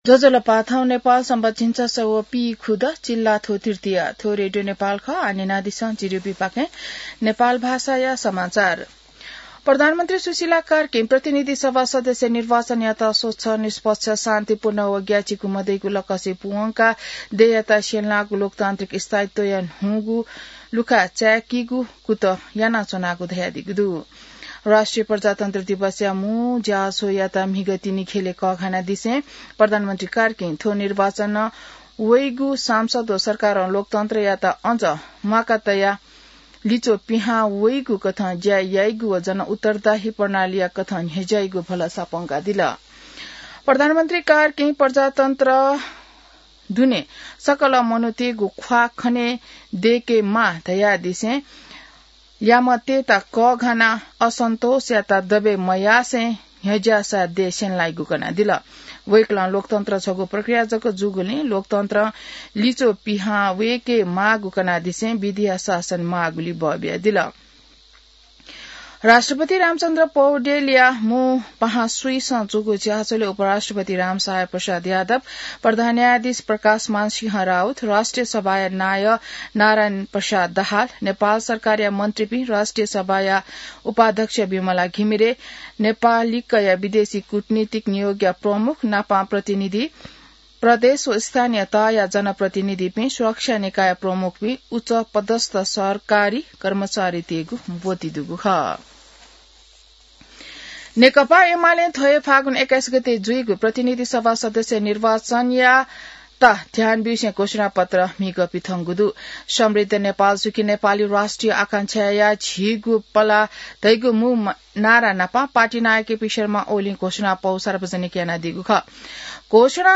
नेपाल भाषामा समाचार : ८ फागुन , २०८२